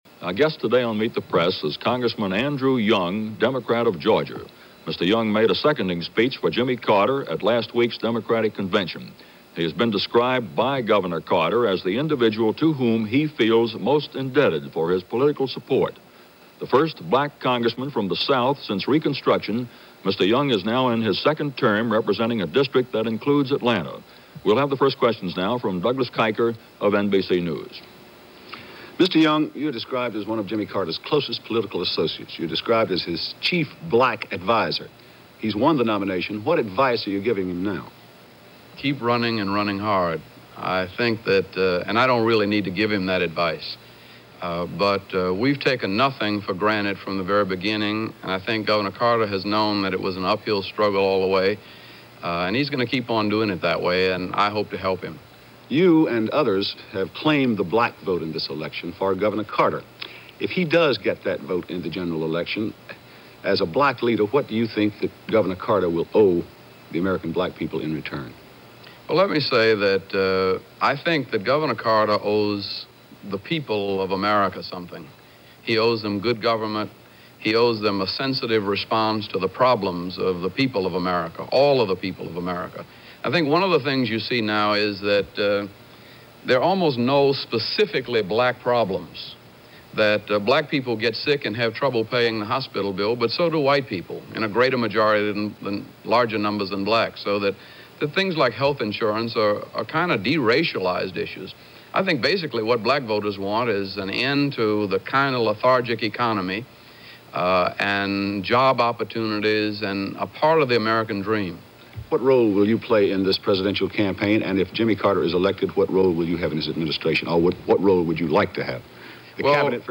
Q&A With Andrew Young - July 18, 1976 - Past Daily Reference Room